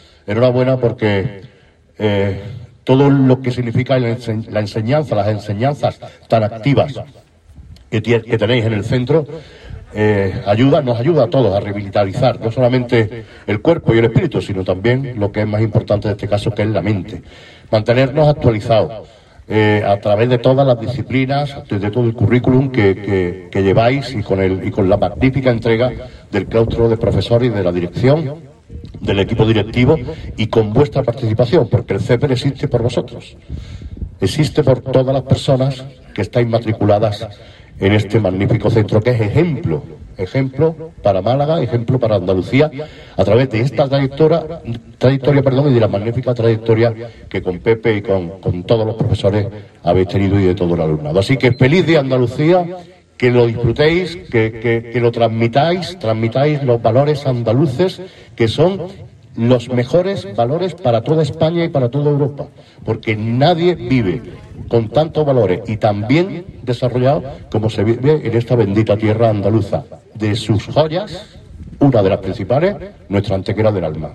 El alcalde Manolo Barón asiste a la conmemoración del Día de Andalucía por parte del CEPER Ignacio de Toledo de Antequera
Felizx Día de Andalucía", manifestaba el Alcalde en su saludo a los presentes.
Cortes de voz